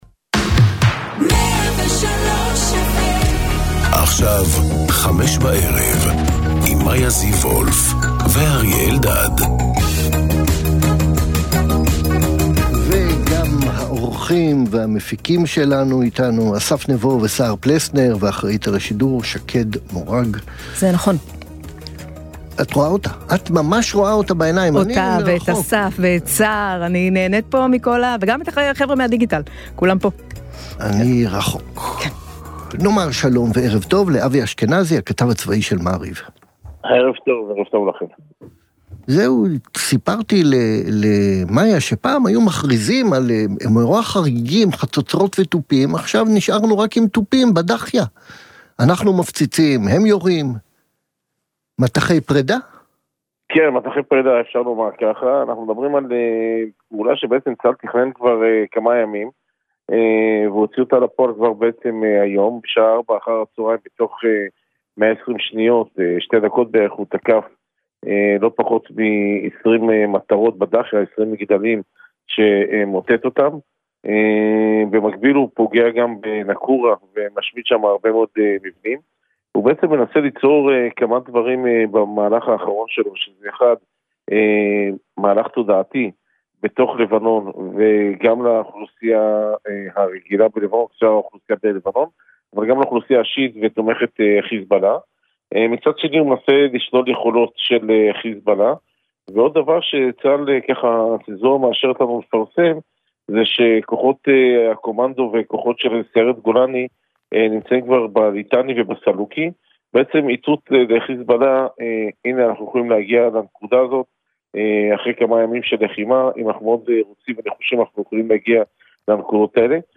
בן כספית, מהעיתונאים הבכירים והבולטים כיום במדינת ישראל, ופרופסור אריה אלדד, רופא, פובליציסט וכמובן חבר כנסת לשעבר מטעם האיחוד הלאומי ועוצמה לישראל, מגישים יחד תכנית אקטואליה חריפה וחדה המורכבת מריאיונות עם אישים בולטים והתעסקות בנושאים הבוערים שעל סדר היום.